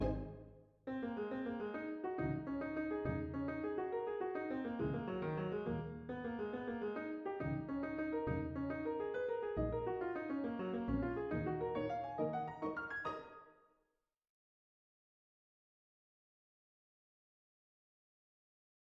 En fait par le si dièse, on se trouve en tonalité de sol dièse majeur, pour s’acheminer à la fin de la séquence sur la magnifique montée arpégée de l’accord de ré dièse mineur avec 7eme, 9eme et 11eme.
L’accompagnement est réalisé par des cordes en pizz et des vents ayant repris l’ostinato.